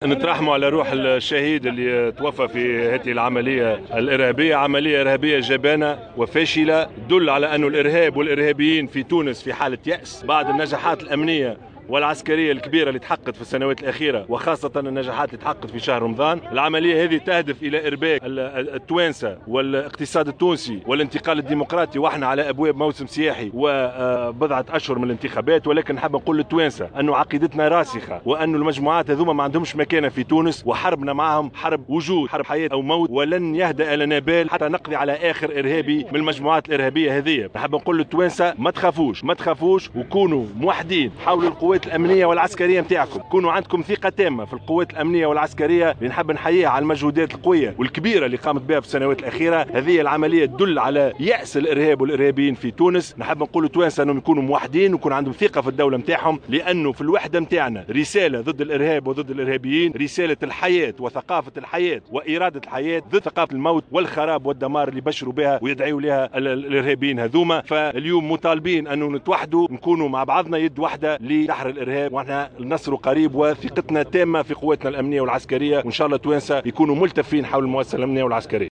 تصريح رئيس الحكومة